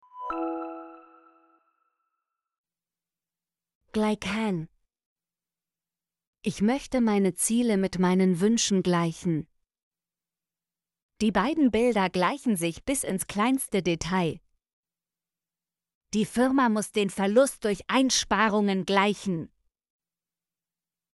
gleichen - Example Sentences & Pronunciation, German Frequency List